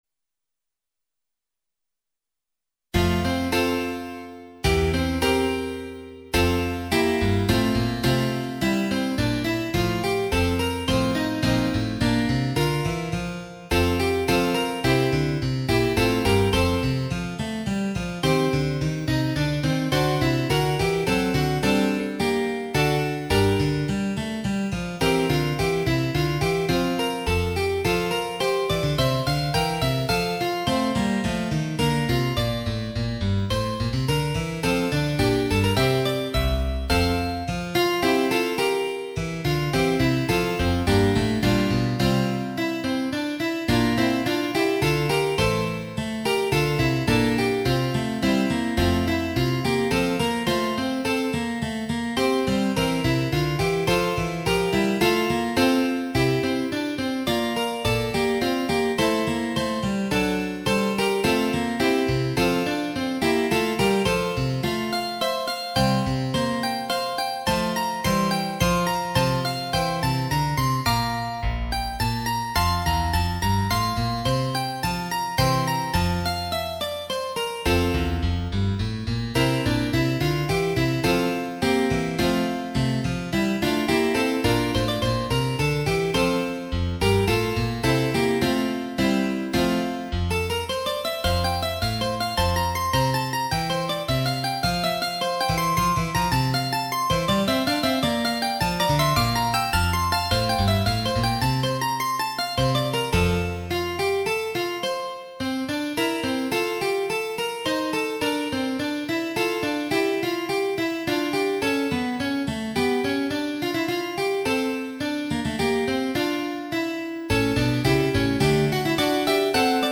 Canon of three voices "Dona Nobis Pacem"　< 平和をわれらに >
- 3声部(一部4声)輪唱 - 器楽伴奏付
後半では間奏を経て今度は逆の順番で各声部が加わり、最後だけ4声部となって終わります。